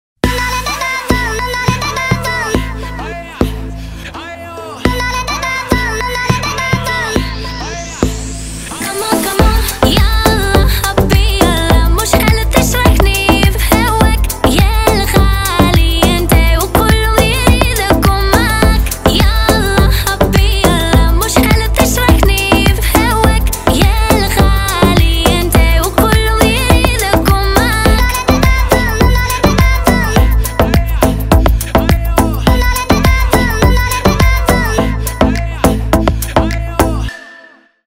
поп
восточные
Eastern